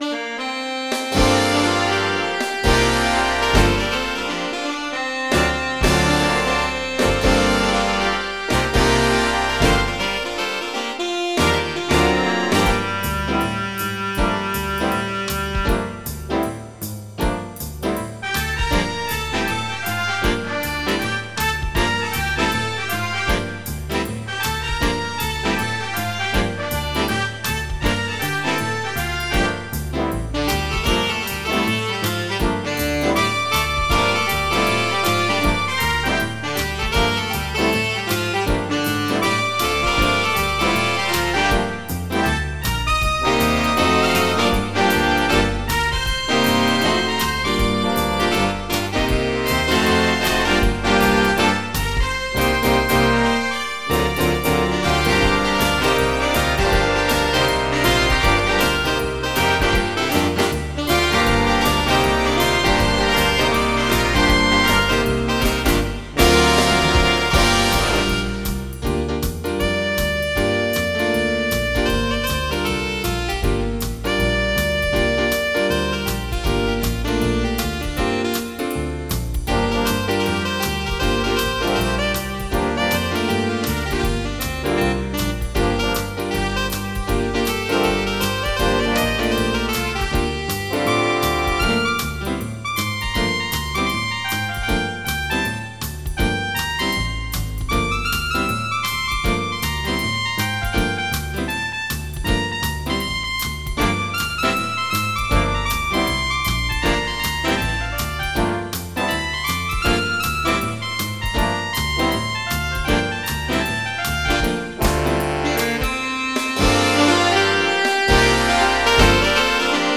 Jazz
MIDI Music File
General MIDI